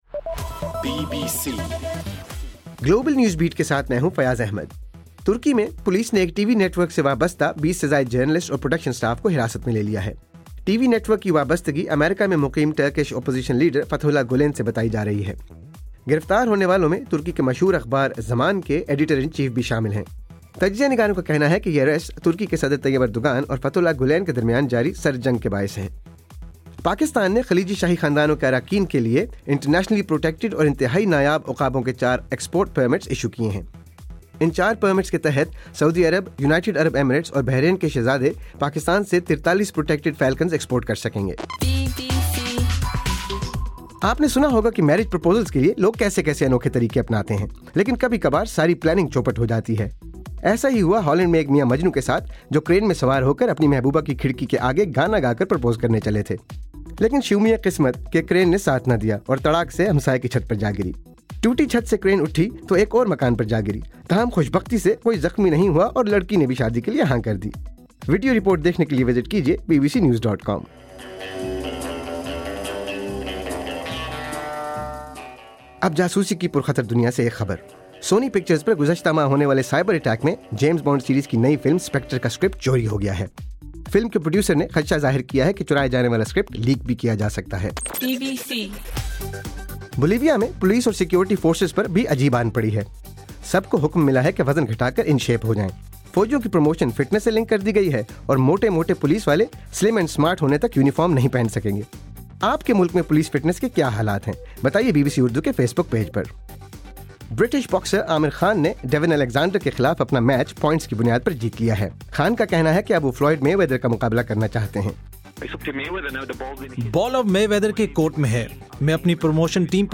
دسمبر 14: رات 9 بجے کا گلوبل نیوز بیٹ بُلیٹن